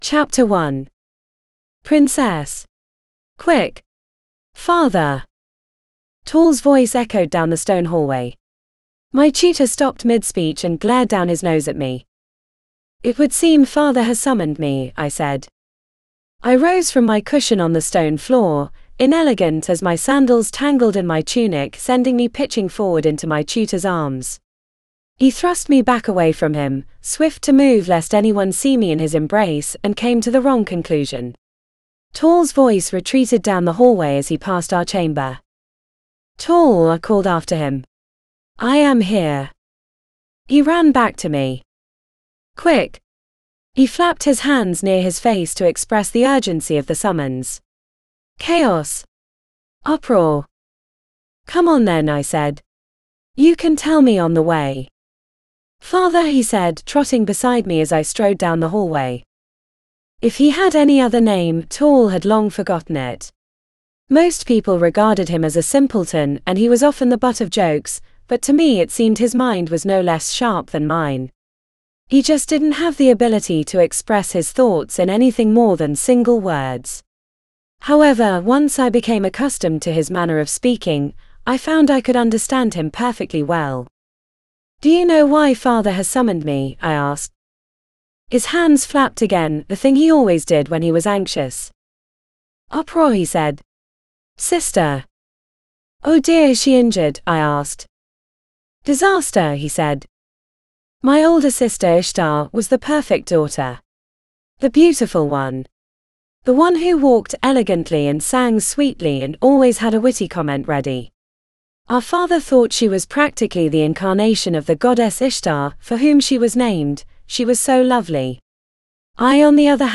Narrator: This audiobook is digitally narrated using the voice of Anya and produced by Google Play.